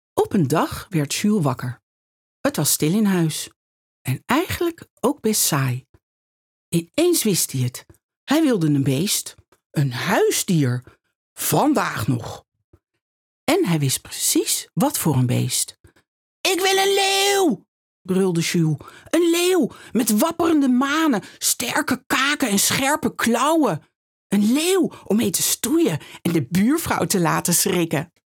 Travieso, Versátil, Seguro, Amable, Cálida
Comercial